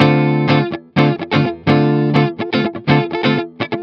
03 GuitarFunky Loop D.wav